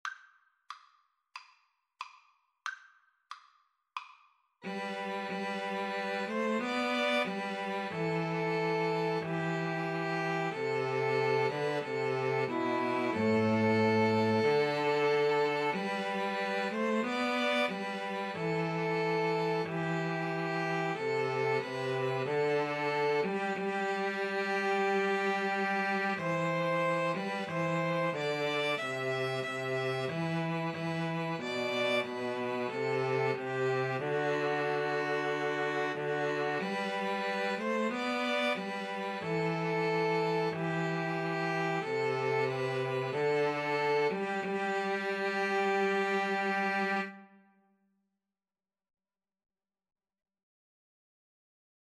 Andante = c. 92
2-Violins-Cello  (View more Easy 2-Violins-Cello Music)
Classical (View more Classical 2-Violins-Cello Music)